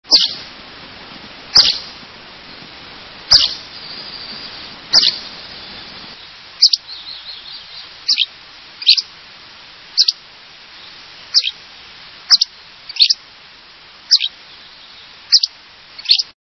Hammond's Flycatcher
Hammonds_Flyicatcher.mp3